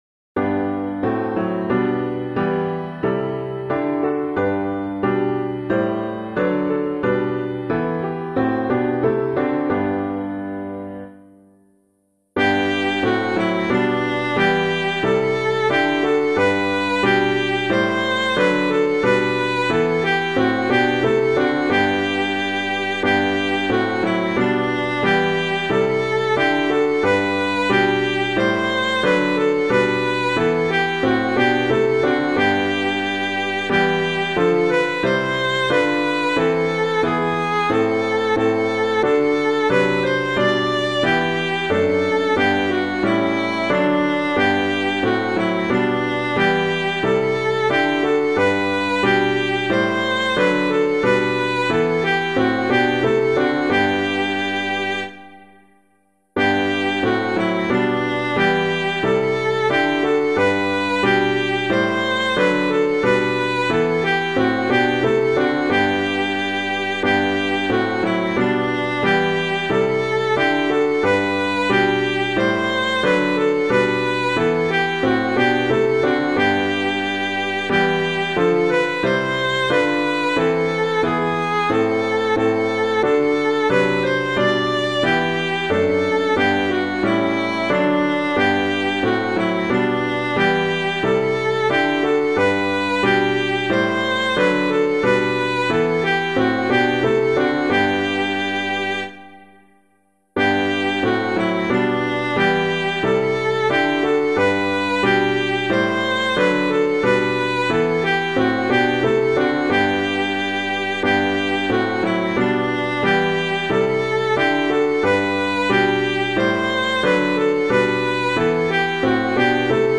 piano
God Whose Giving Knows No Ending [Edwards - IN BABILONE] - piano.mp3